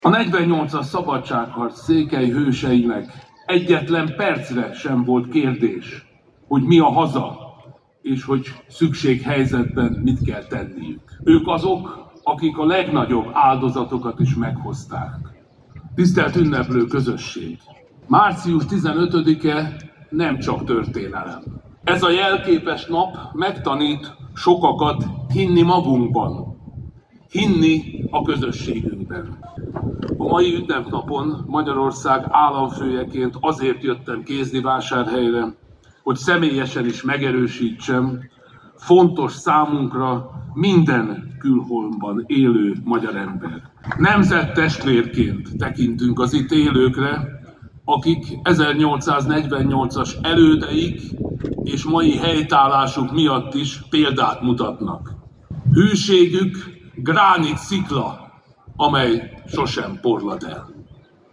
A magyar államfő az 1848–49-es forradalom és szabadságharc évfordulója alkalmából rendezett ünnepségen mondott beszédet a háromszéki város főterén összegyűlt tömeg előtt.
SulyokTamas_marcius-15-beszed-reszlet.mp3